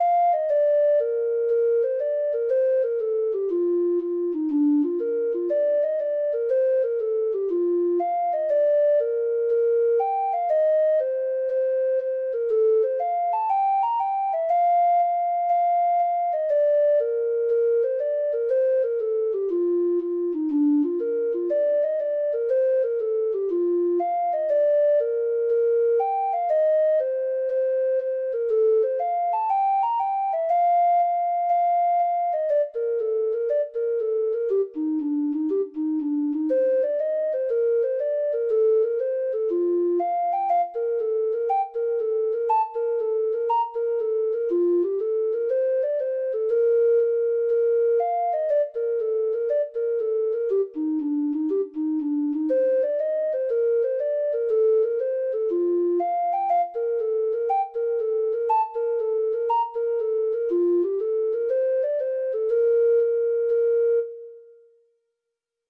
Hornpipes